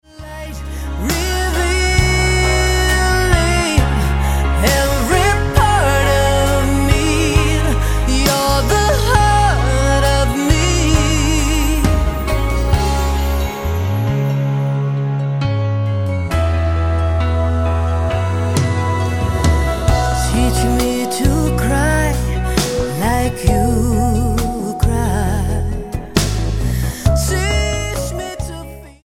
STYLE: Pop
has a big orchestral arrangement after a tinkly synth intro